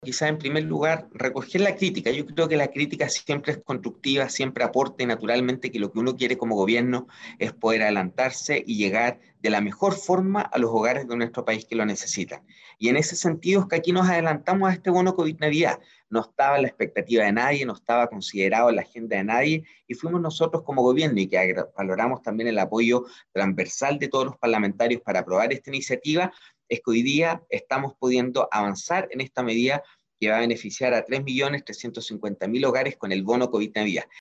Al respecto, conversamos con el Subsecretario de Servicios Sociales, Sebastián Villarreal, quién precisó que en el caso de la región del Biobío serán las familias de las comunas de Lota, Coronel y Cañete las que recibirán $55 mil por cada integrante del grupo familiar.